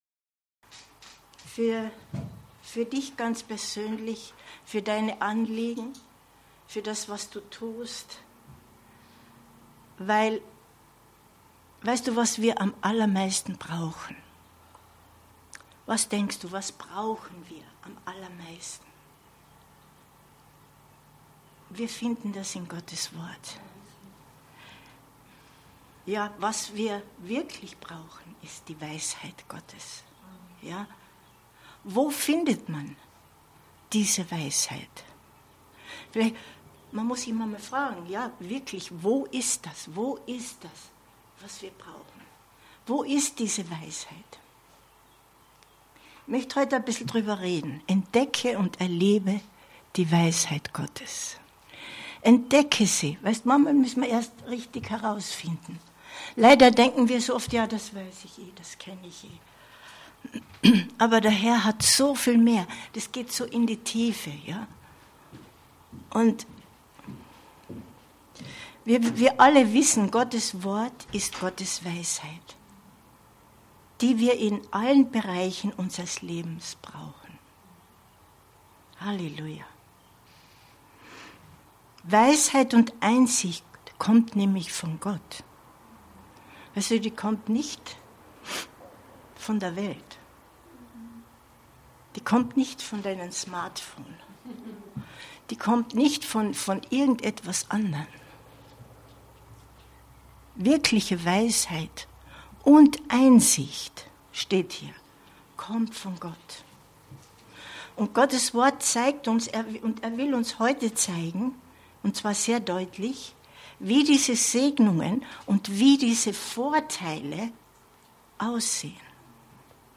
Entdecke und erlebe die Weisheit Gottes 18.06.2023 Predigt herunterladen